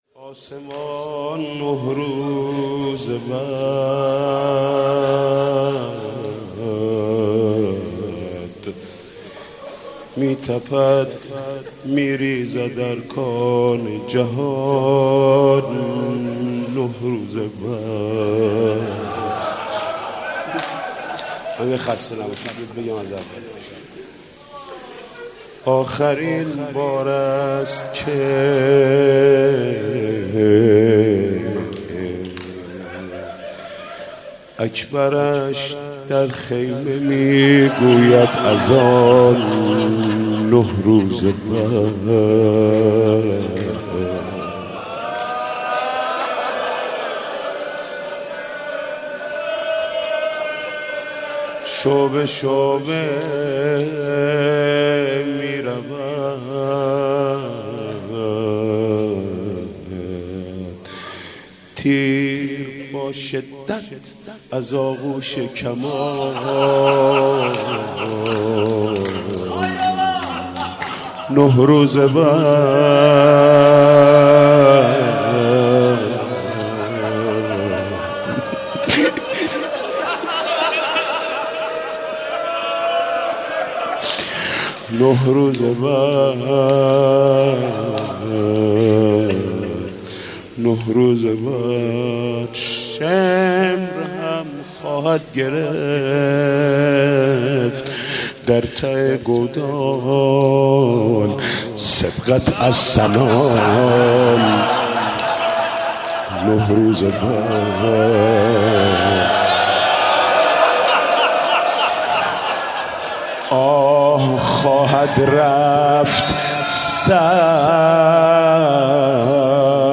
مداحی جدید حاج محمود کريمی شب دوم محرم97 هيأت راية العباس